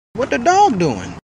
What The Dog Doing Meme sound effects free download